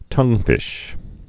(tŭngfĭsh)